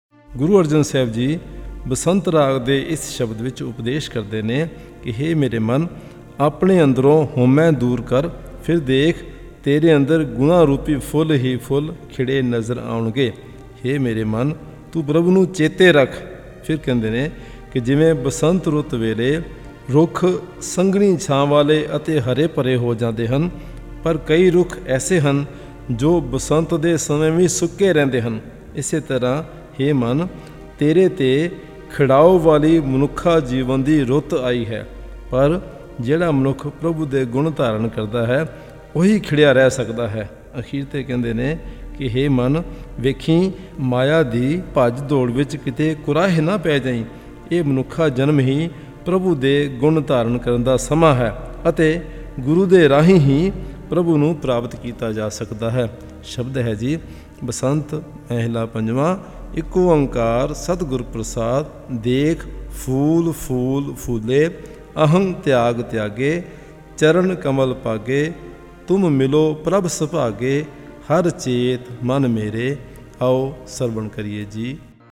Raag Basant Bilawal